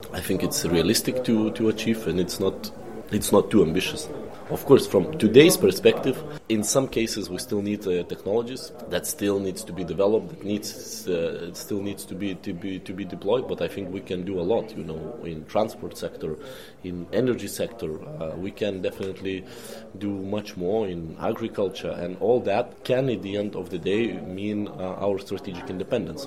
Jedan od govornika na jučerašnjem otvaranju prve Konferencije ovog tipa u Zagrebu bio je, između ostalih, povjerenik Europske komisije za okoliš, oceane i ribarstvo Virginijus Sinkevičius koji je tim povodom gostovao u Intervjuu Media servisa.